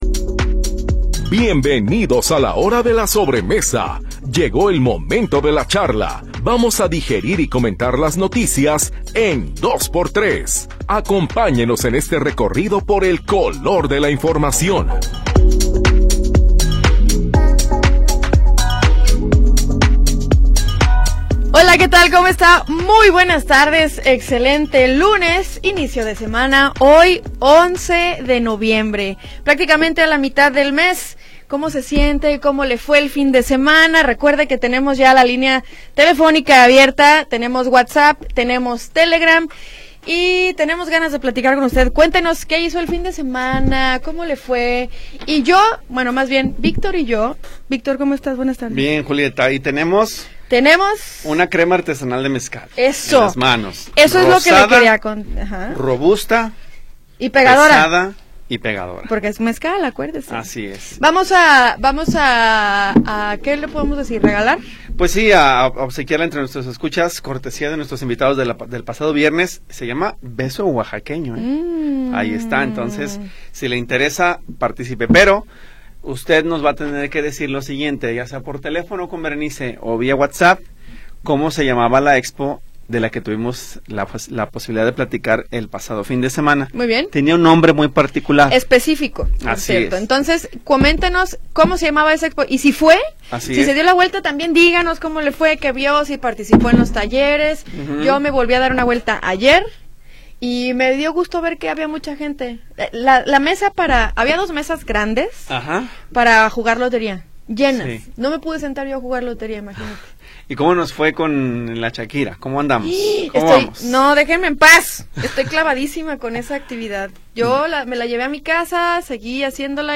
Todo el color de la noticia en una charla sabrosa después de la comida.